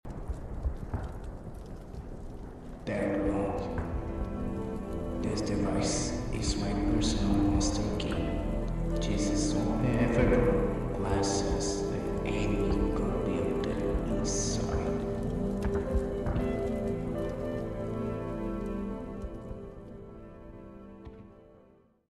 voice test (trying to make a voice similar to Mr. Boom)